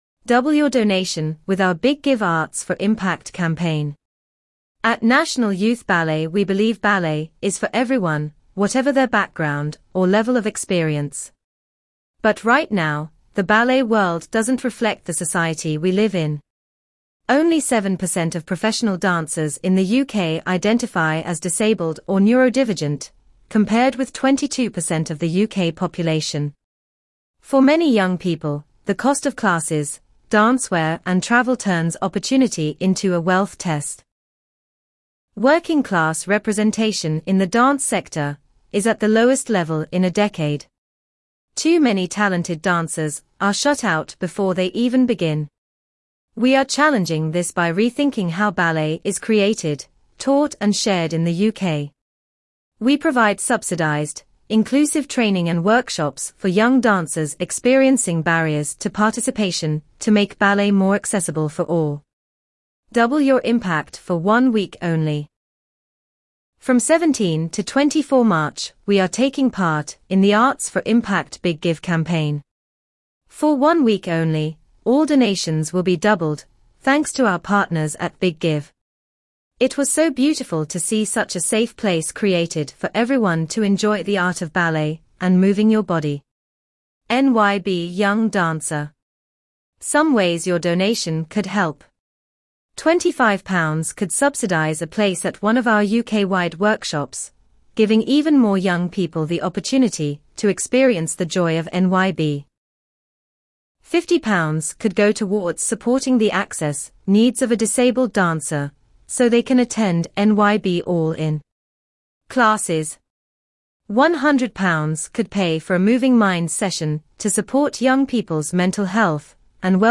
You can find an audio description of this page here.